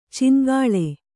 ♪ cin gāḷe